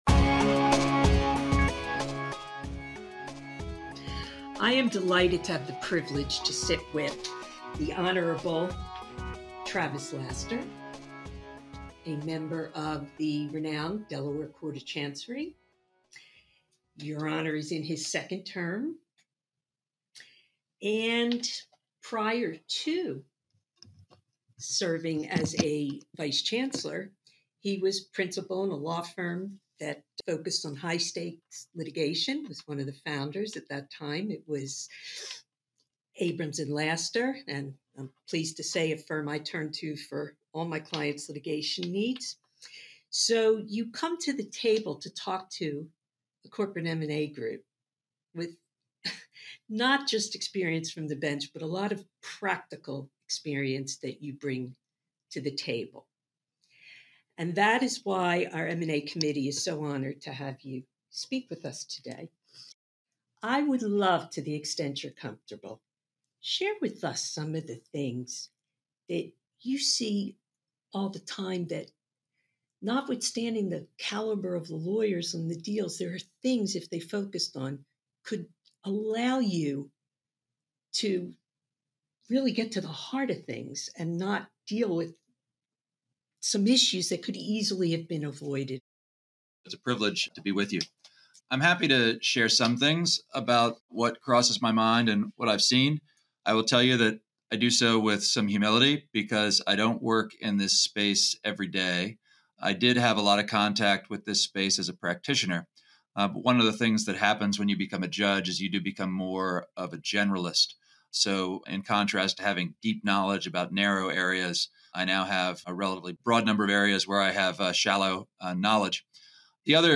Interview with VC Laster of the Delaware Court of Chancery